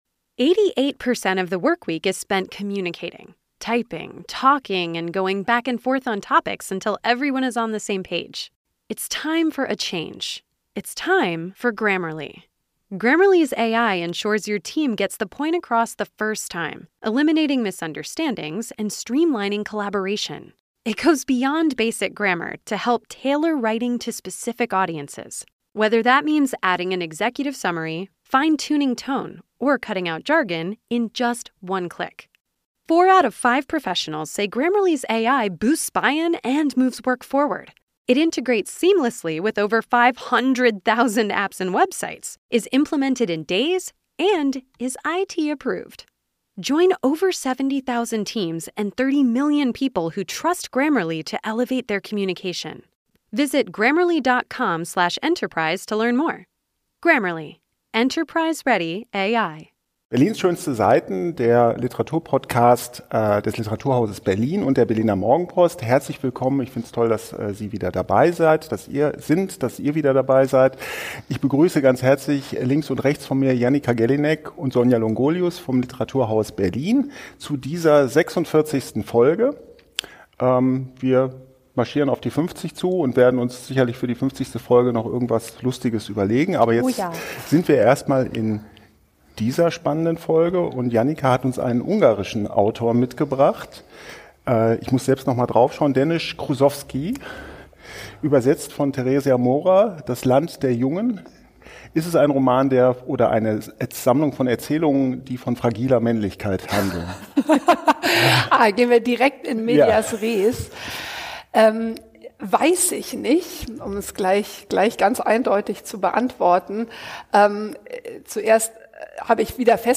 Drei Menschen, die sich für Literatur begeistern, treffen sich nun alle zwei Wochen, um sich darüber zu unterhalten, was sie derzeit im weiten...